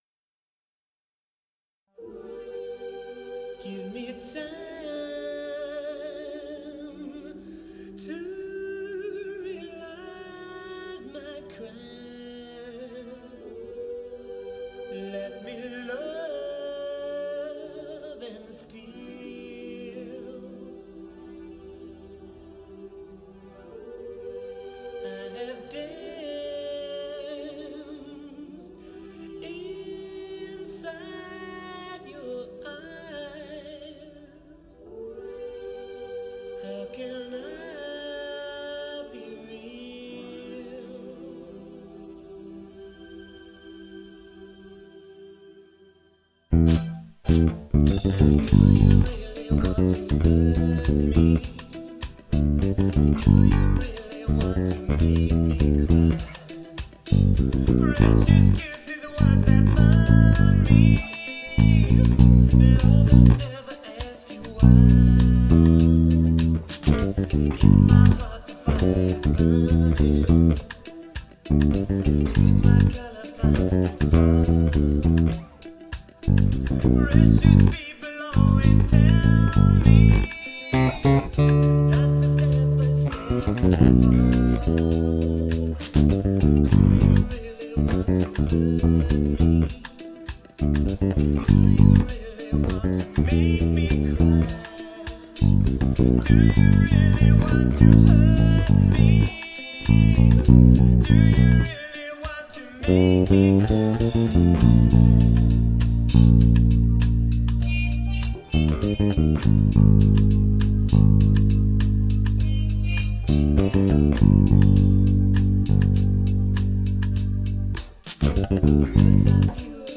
Instrumental / Bass only